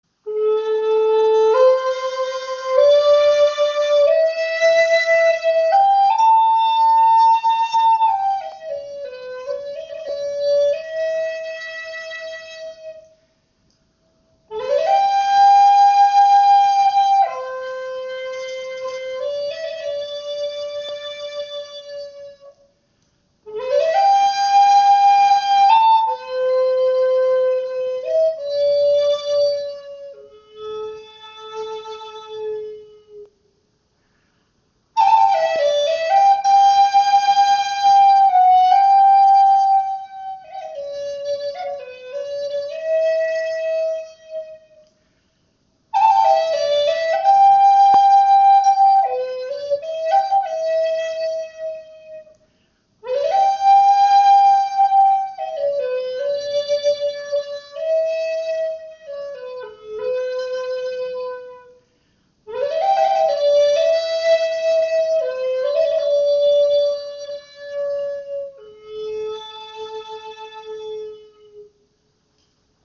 Klangbeispiel
Diese Flöte hat einen angenehmen Luftwiderstand und lässt sich einfach modulieren.